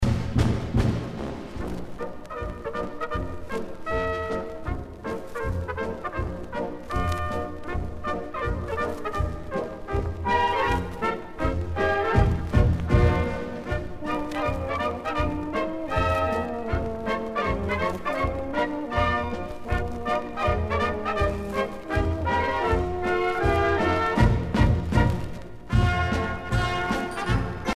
danse : Letkiss
Pièce musicale éditée